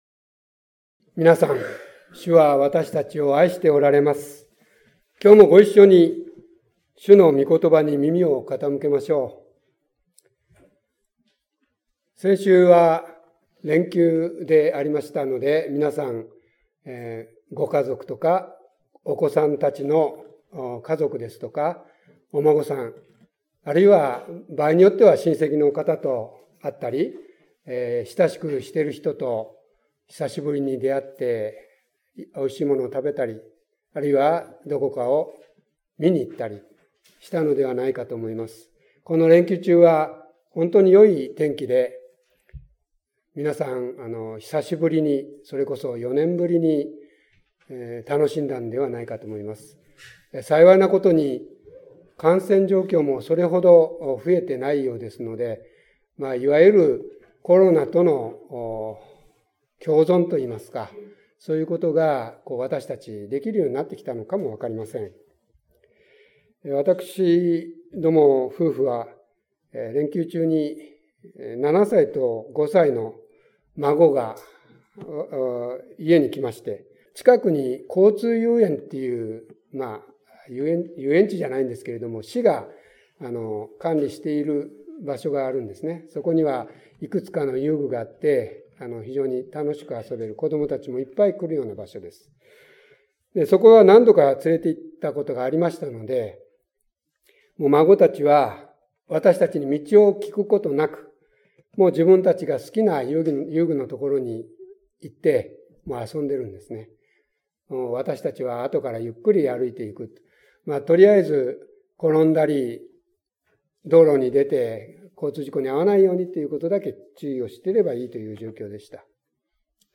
5月7日礼拝説教「キリストは道、真理、命」